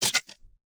Tab Select X3.wav